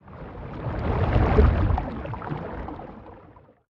Sfx_creature_glowwhale_swim_fast_03.ogg